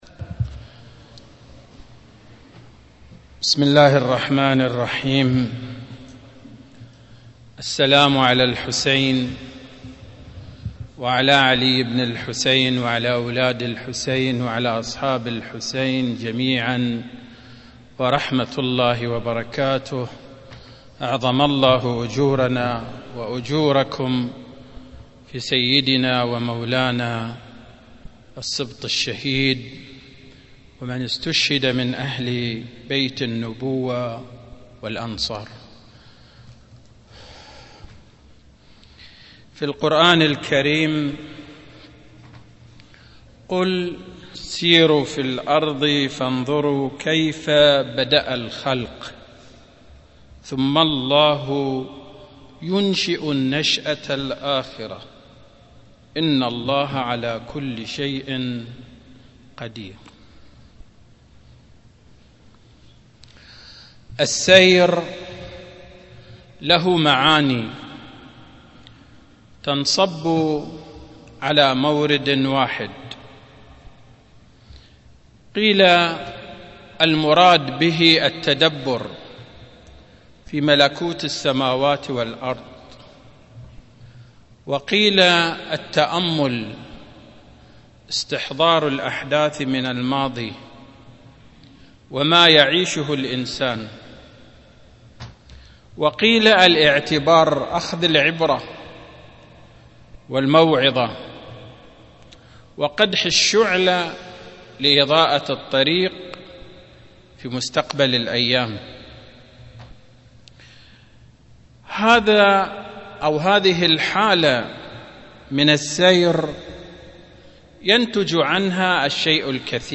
القسم : محاضرات يوم الجمعه بجامع الإمام الحسين عليه السلام